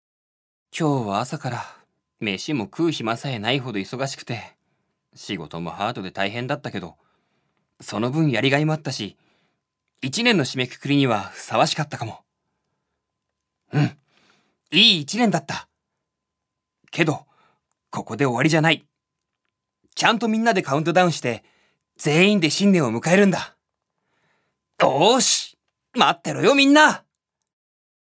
所属：男性タレント
音声サンプル
セリフ２